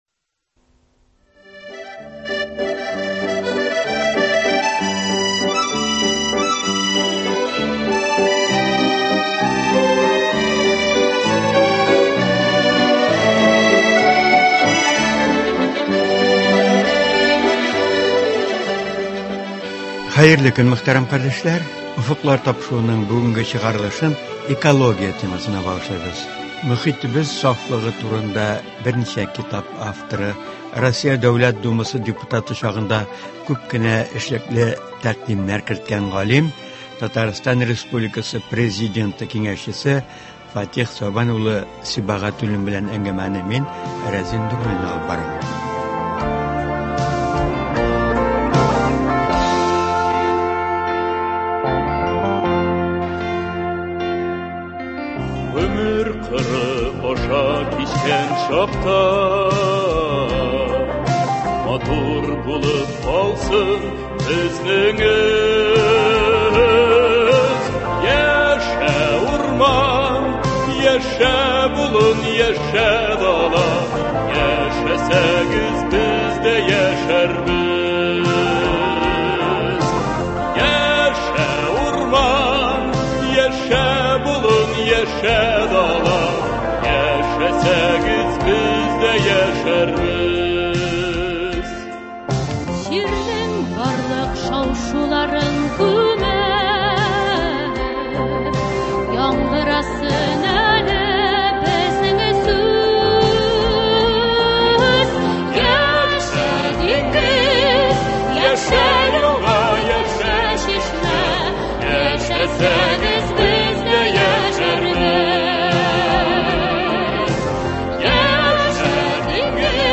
Җәйге чорда мохитебезне тәртипкә китерү, көнкүреш калдыкларыннан чистарту буенча республикабызда төрле чаралар үткәрелә. Аларның кайберләре турында Татарстан республикасы Президенты киңәшчесе, профессор, күпсанлы тарихи китаплар авторы Фатих Сибагатуллин сөйләячәк.